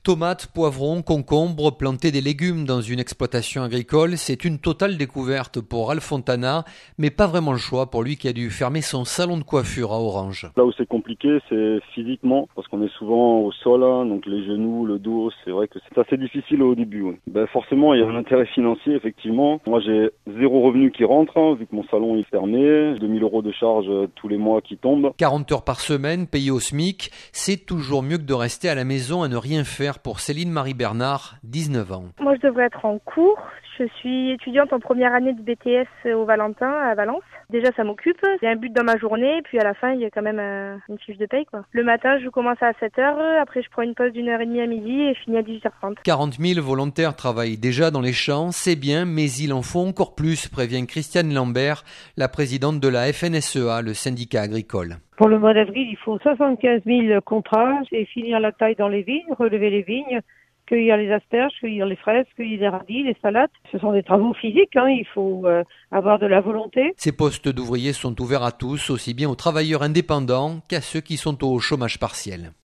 Reportage